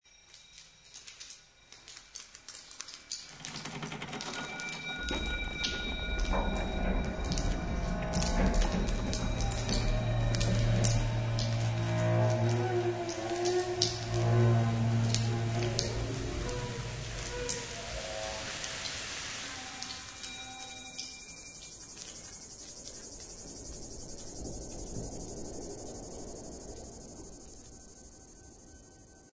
Bande son Ecorces :
Création acousmatique et chorégraphique
Percussions
Contrebasse
Tout commence et finit par le craquement de la carcasse d'un bâteau - grave - craquement qui se déploie à travers la salle, et le son d'une conrebasse en live, d'abord puissance enveloppante,maternelle , nourricière, puis annonciatrice; Sonorités ultra graves laissant sourdre une angoisse:
- 3 personnages musicaux: bande acousmatique, contrebasse et percussions sur objets sonores et matériaux (bambous, cloches, feuilles mortes, eau ...)sont des personnages qui suivent leur propre cheminement, s'introduisent, s'imposent, se retirent, font des clins d'oeil à l'univers visuel.